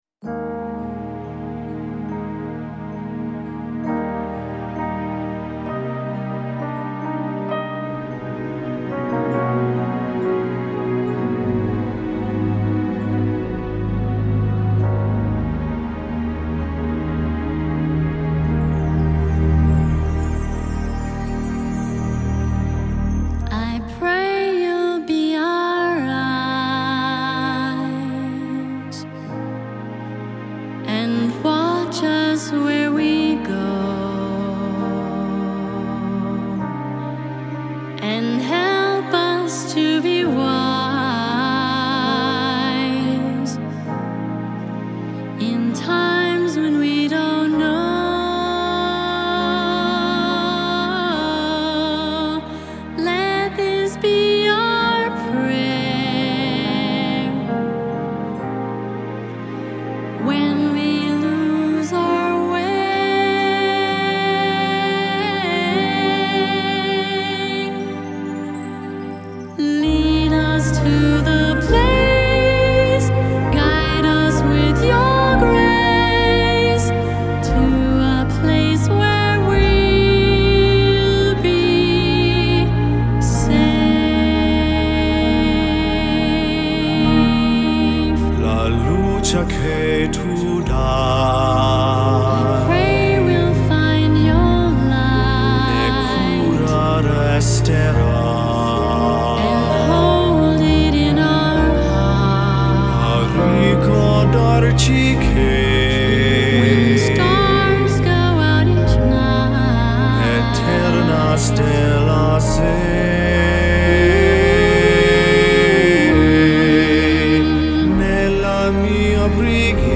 a duet